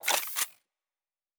Weapon 11 Reload 1 (Rocket Launcher).wav